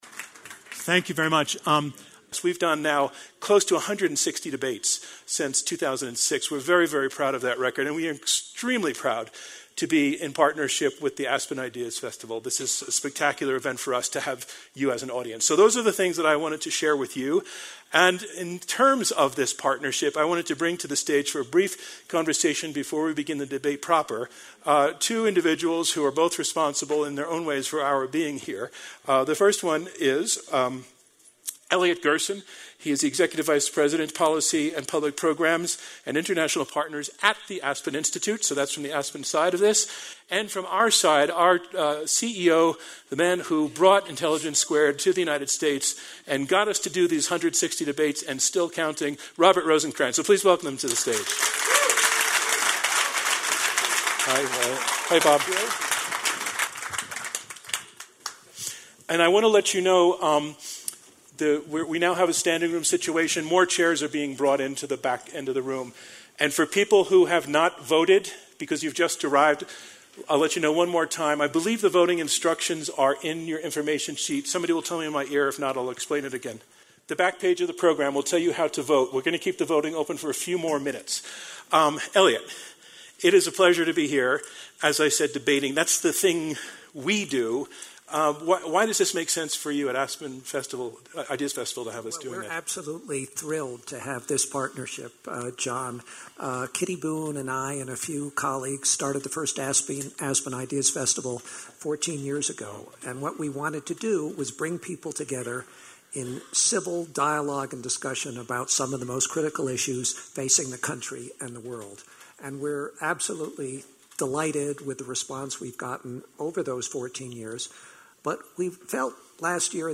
293GLOBALIZATIONREVISITEDIntelligenceSquaredLiveDebateGlobalizationHasUnderminedtheAmericanWorkingClass.MP3